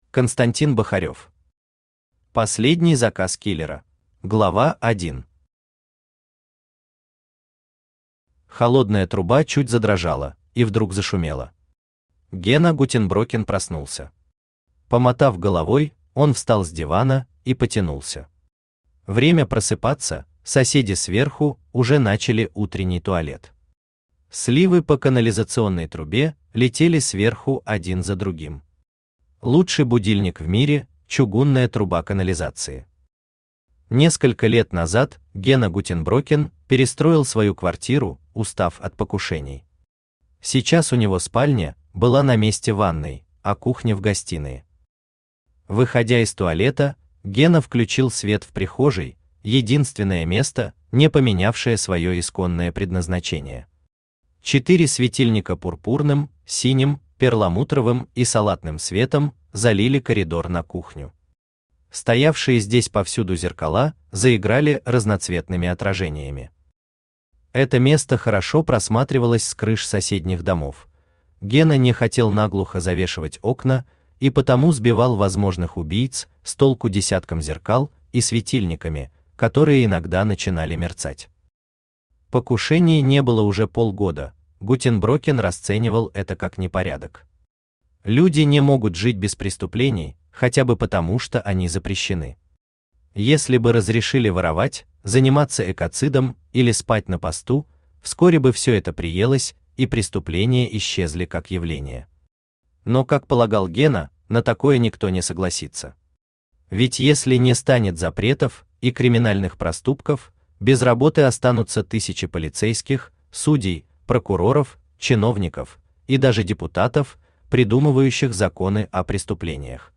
Аудиокнига Последний заказ киллера | Библиотека аудиокниг
Aудиокнига Последний заказ киллера Автор Константин Павлович Бахарев Читает аудиокнигу Авточтец ЛитРес.